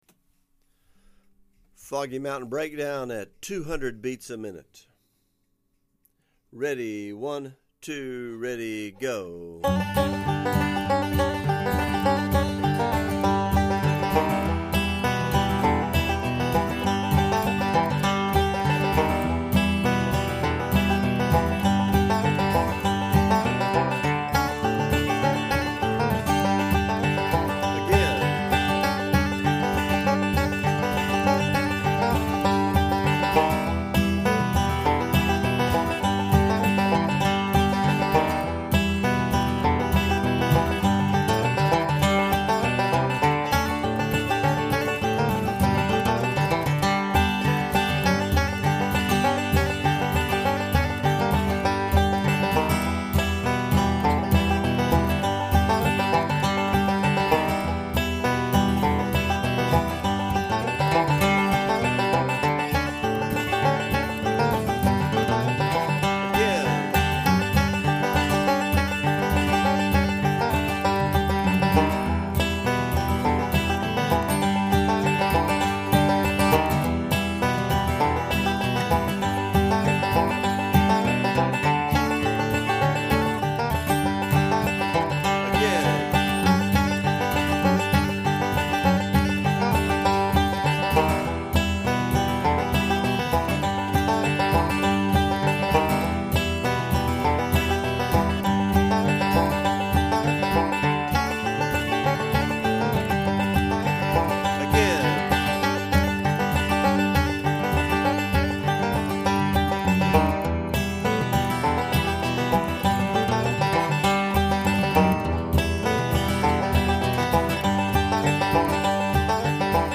200 bpm